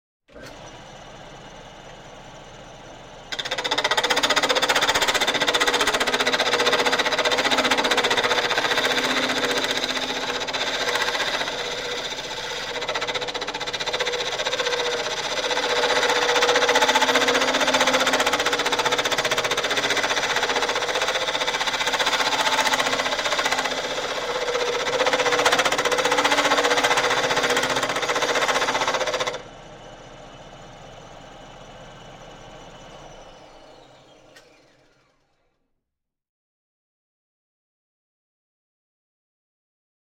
Звуки токарного станка
Шум токарного станка